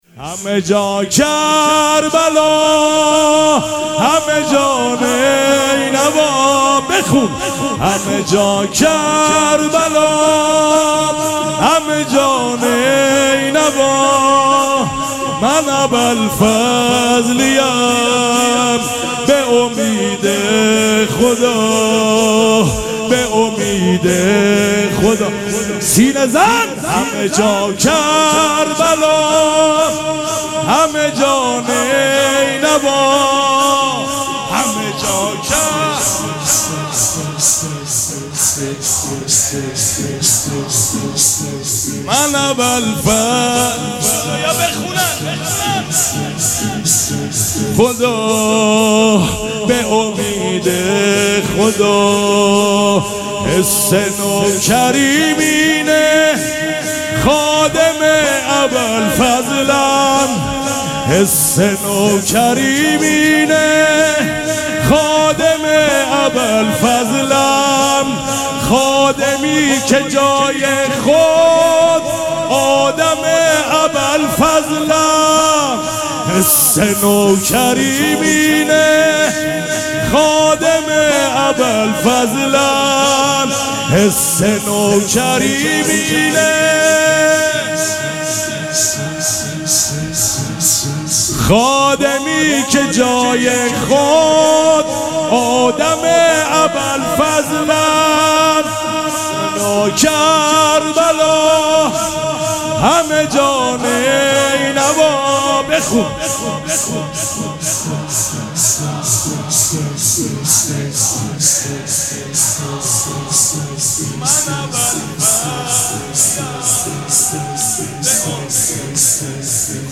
شب اول مراسم عزاداری اربعین حسینی ۱۴۴۷
شور